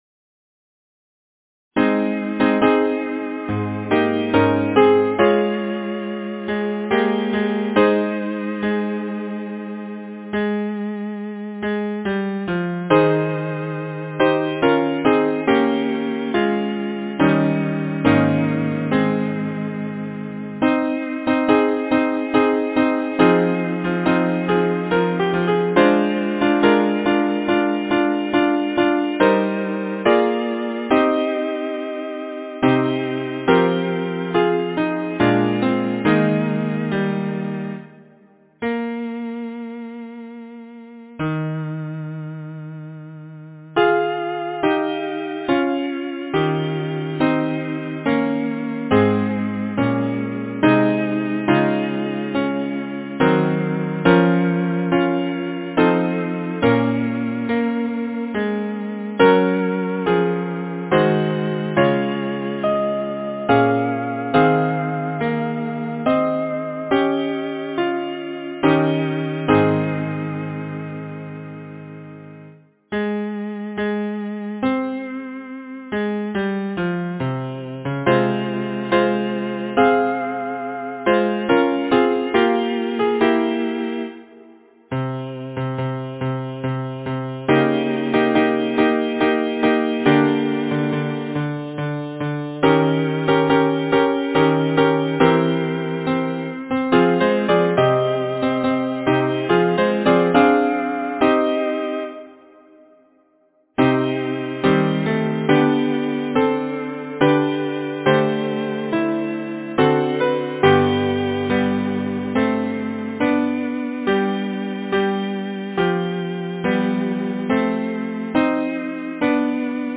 Title: Through the day Thy love has spared us Composer: John Ebenezer West Lyricist: Thomas Kelly Number of voices: 4vv Voicing: SATB Genre: Sacred, Anthem
Language: English Instruments: a cappella or Keyboard